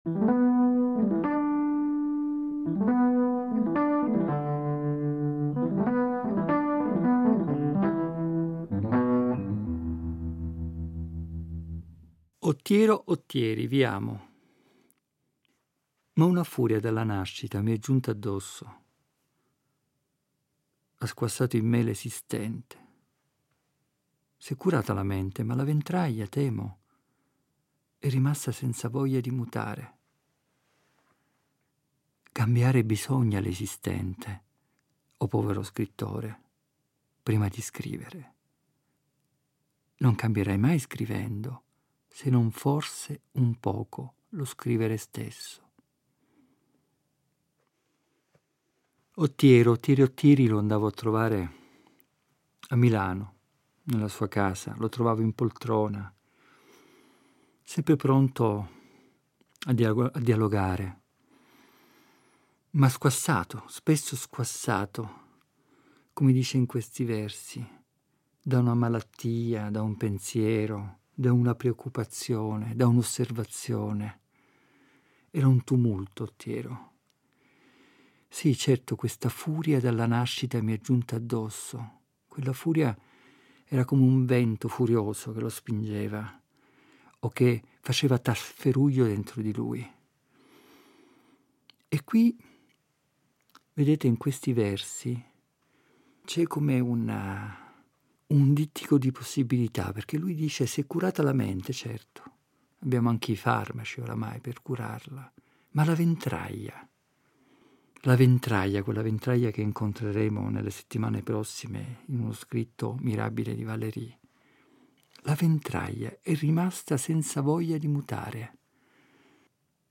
Ed è a partire da questo simbolo «delle equazioni casalinghe» che hanno caratterizzato il tempo del lockdown (una parte delle registrazioni è stata pensata e realizzata proprio fra le mura domestiche) che egli ci guida nella rigogliosa selva della parola poetica per «dare aria ai pensieri». Ritardi che cambiano la luce delle giornate, avvisi ai naviganti, requiem per un padre poco conosciuto, meditazioni su cosa sia l’opera di un artista a fronte della sua vita: è quanto si troverà nei versi proposti questa settimana.